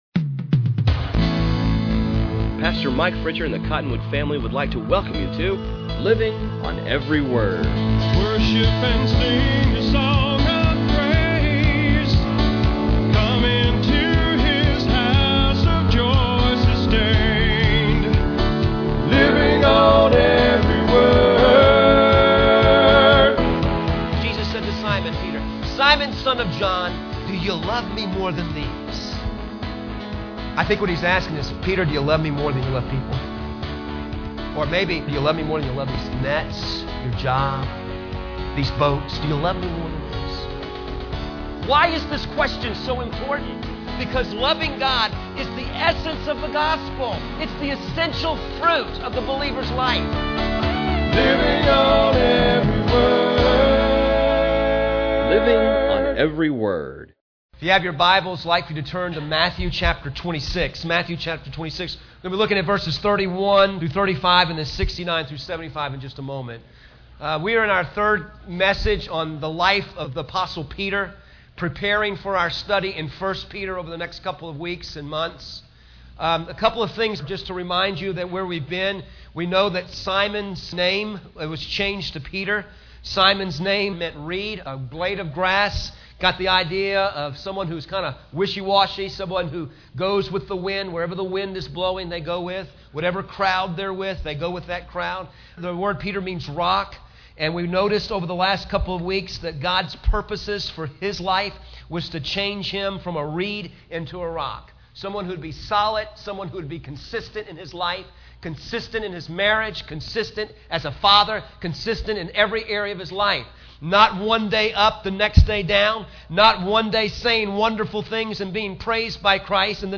sermons on cd